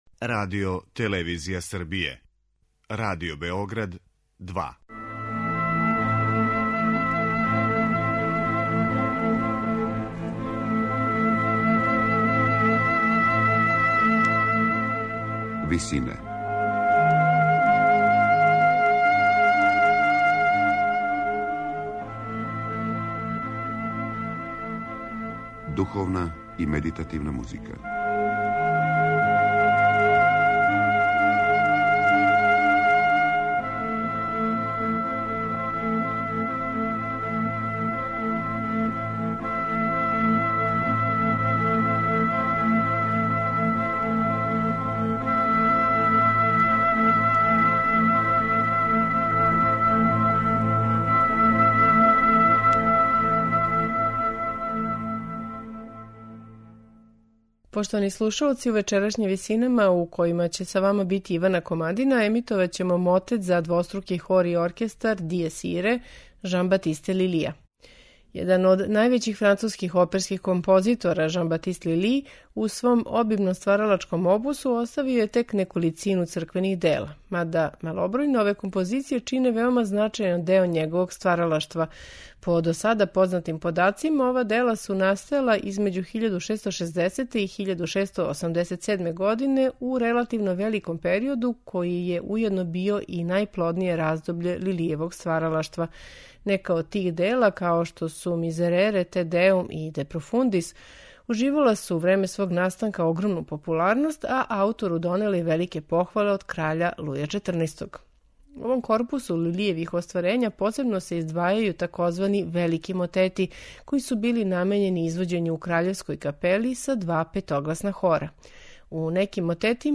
мотет за двоструки хор и оркестар
сопран
мецо-сопран
тенори
бас